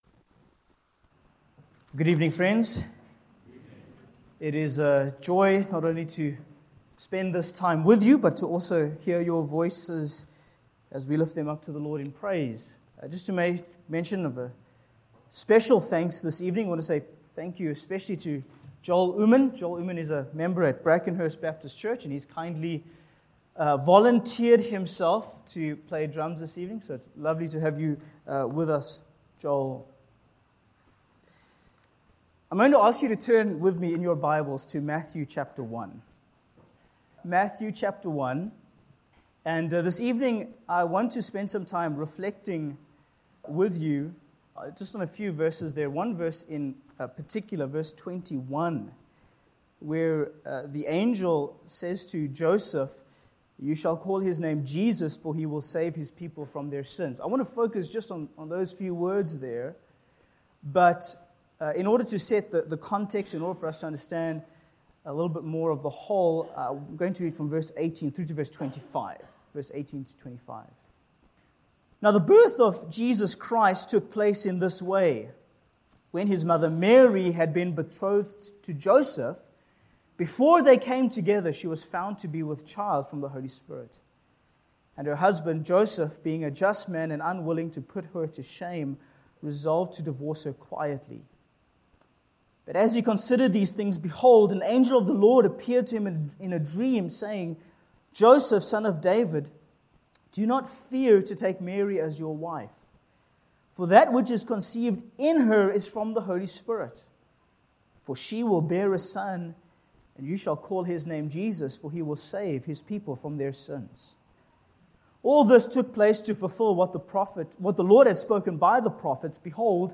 Sermon Points: 1. The Natural Human Condition